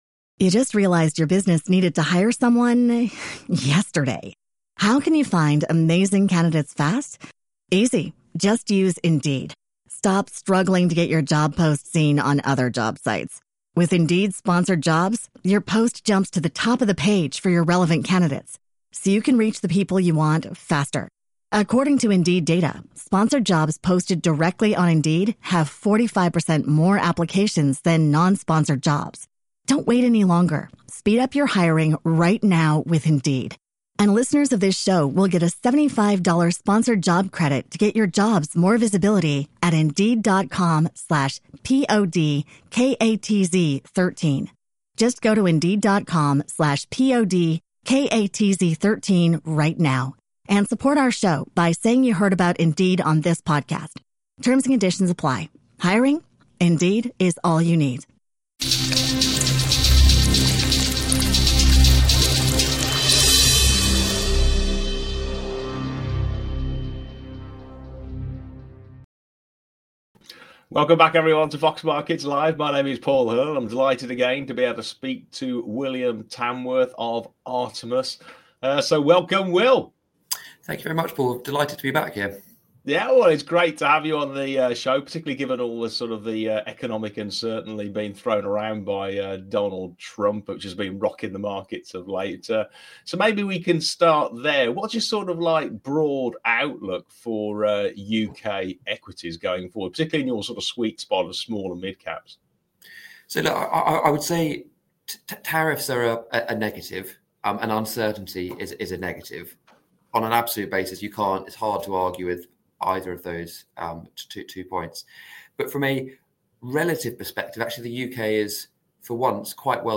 In this fascinating interview